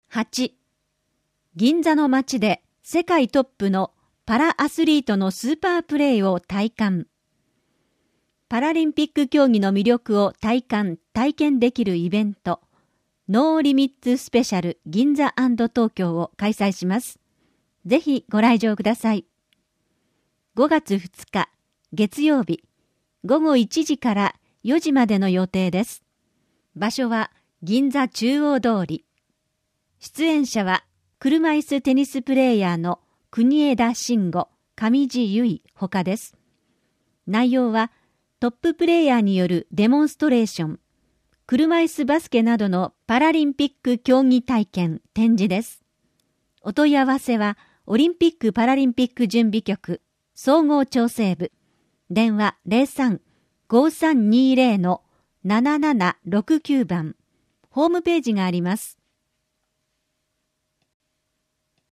「広報東京都 音声版」は、視覚に障害のある方を対象に「広報東京都」の記事を再編集し、音声にしたものです。